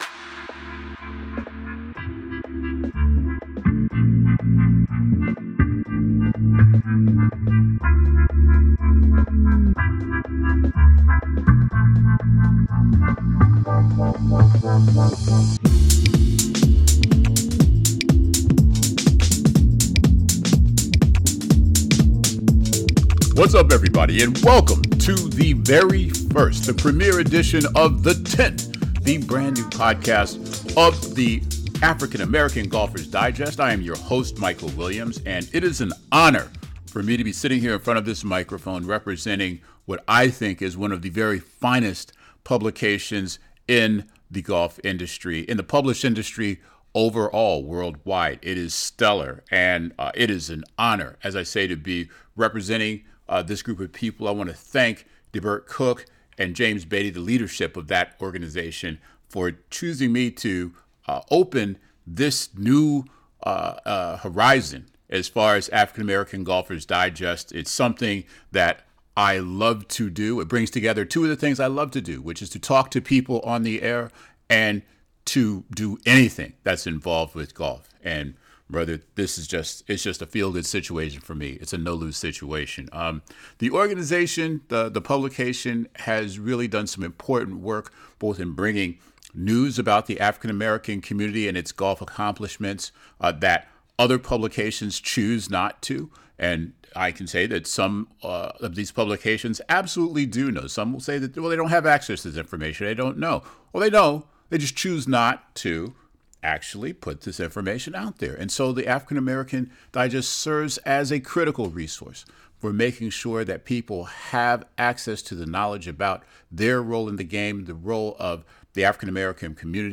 From travel to test equipment, from health to Hollywood, The Tent podcast is essential listening for golfers and non-golfers alike. This week's special guest is former All-Star guard for the "Showtime" Los Angles Lakers, the great Byron Scott!